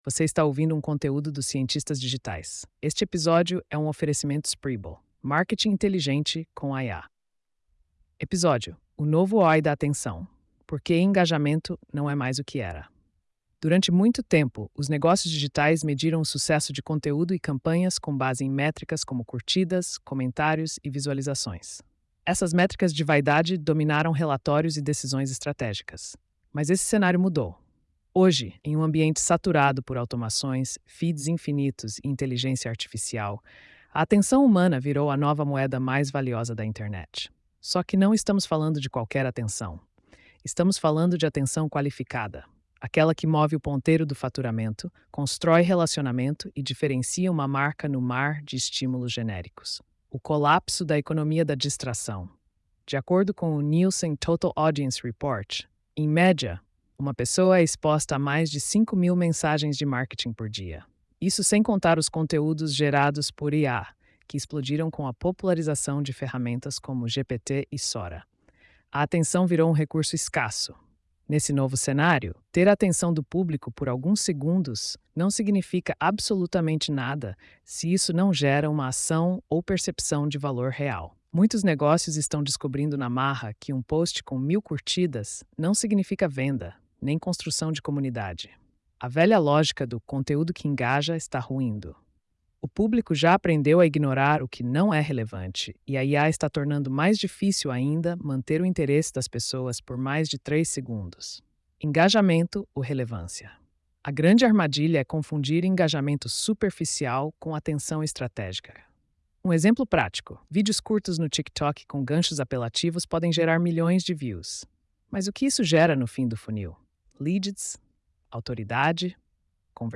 post-3871-tts.mp3